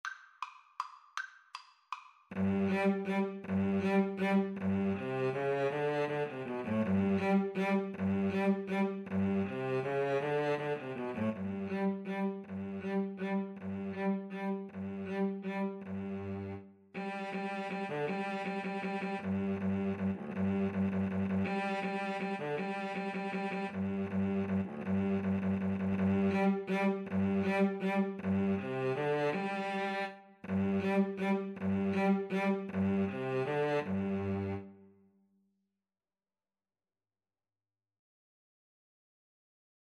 3/4 (View more 3/4 Music)
Classical (View more Classical Violin-Cello Duet Music)